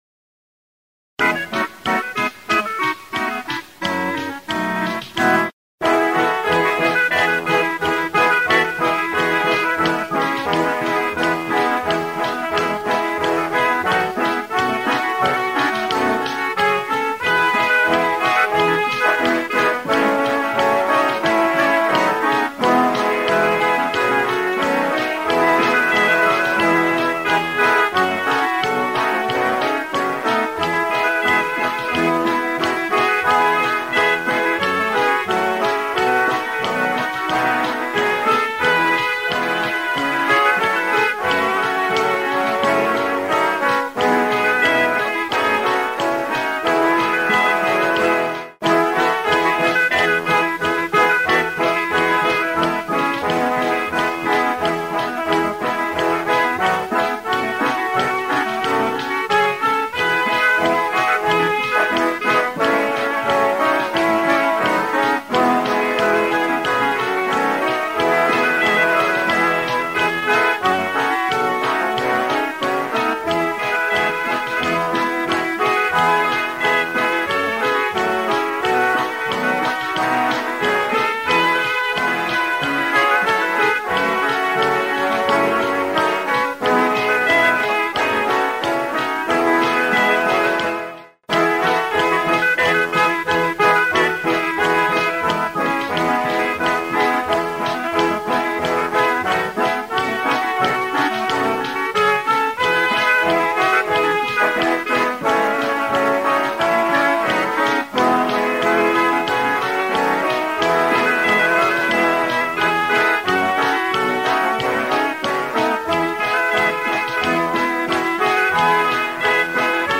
Click to play PoerukaayathamAagivitoem_1 [This accompaniment includes a short prelude]